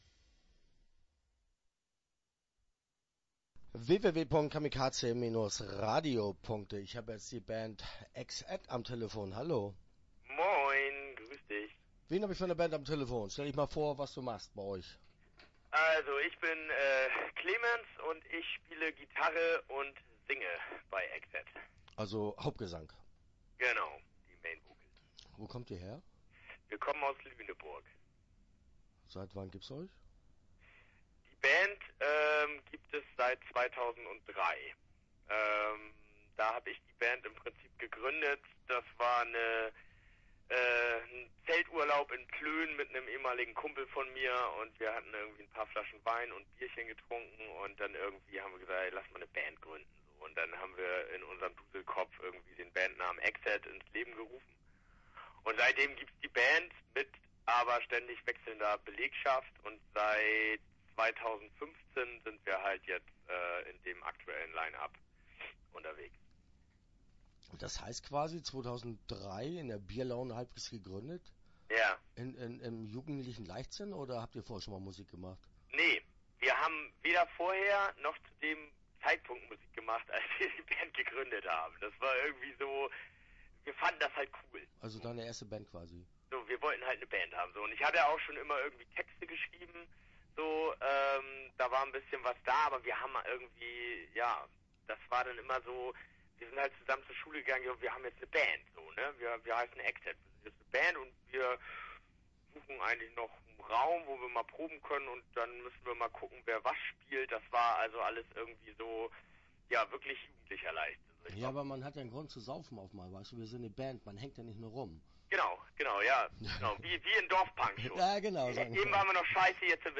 EXAT - Interview Teil 1 (12:10)